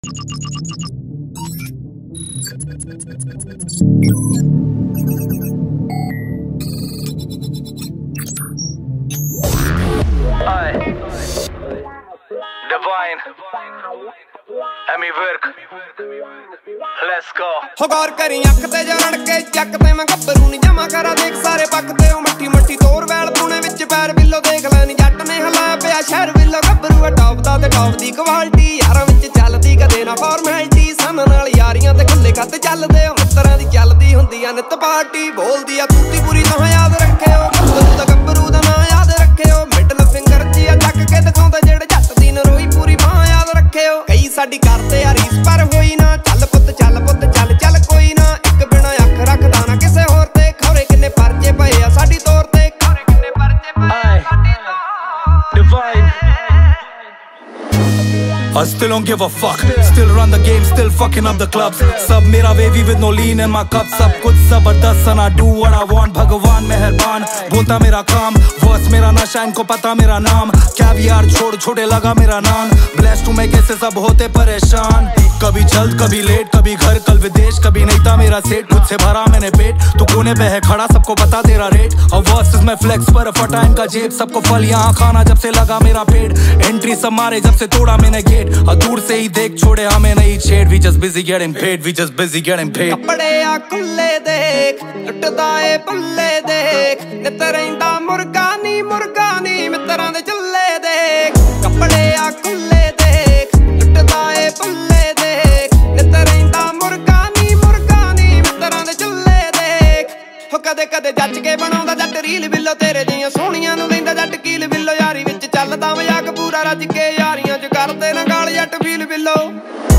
Category :Punjabi Music